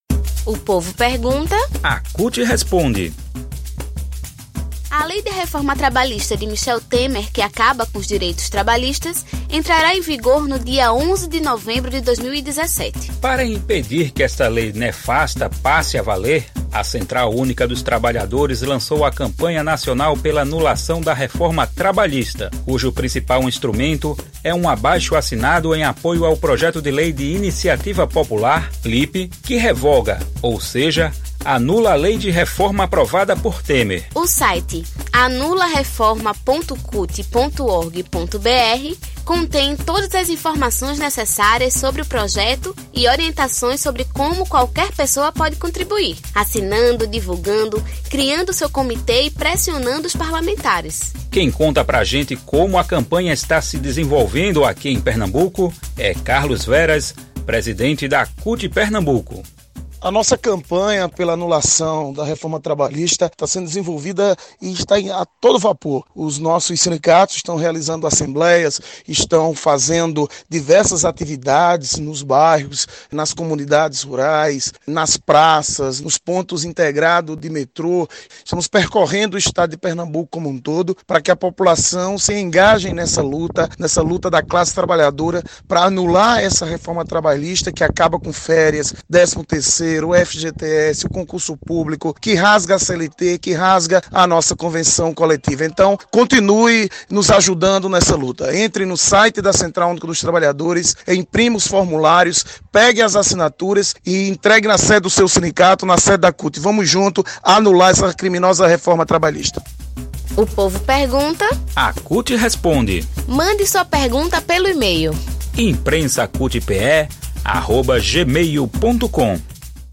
Quem conta para gente como a campanha está se desenvolvendo aqui em pernambuco é Carlos Veras, presidente da CUT PE.